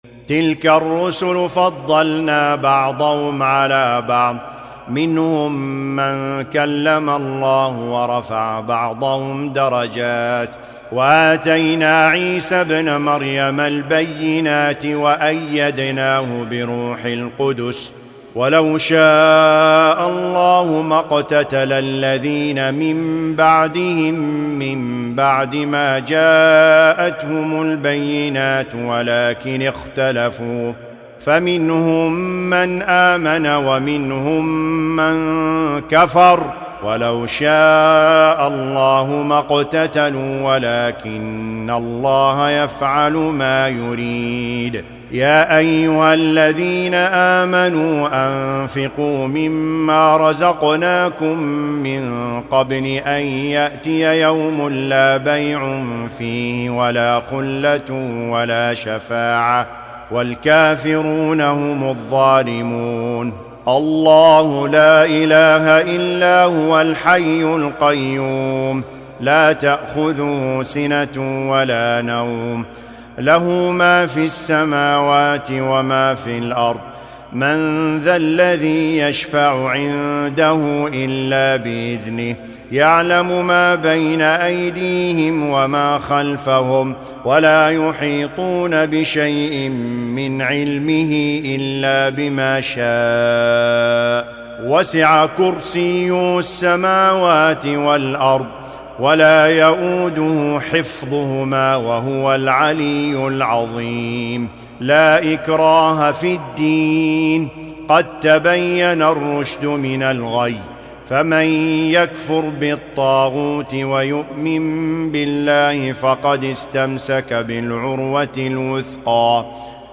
الجزء الثالث : سورتي البقرة 252-286 و آل عمران 1-92 > المصحف المرتل